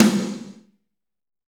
Index of /90_sSampleCDs/Northstar - Drumscapes Roland/DRM_Fast Rock/SNR_F_R Snares x